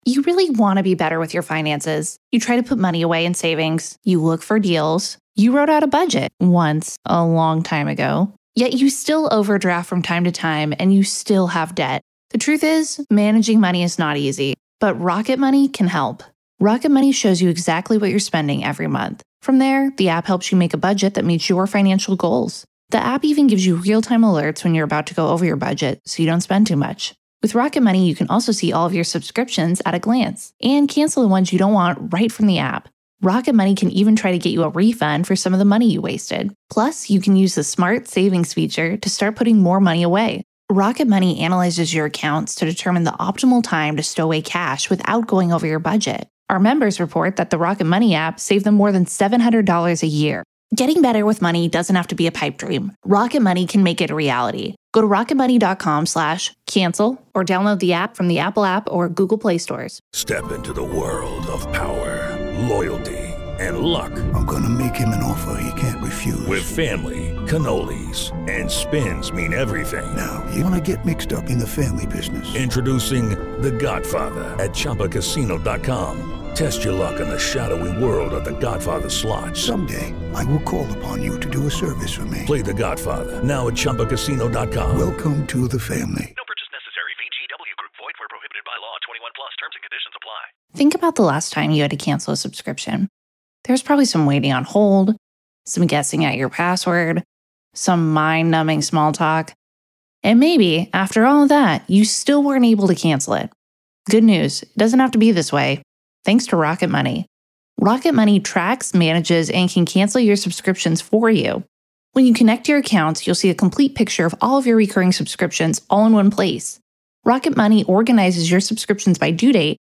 In this powerful interview